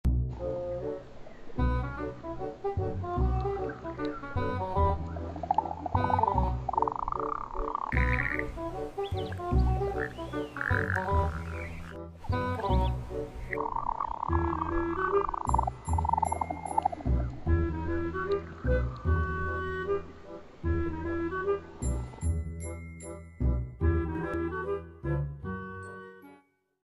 Turtles Mating In Front Of Sound Effects Free Download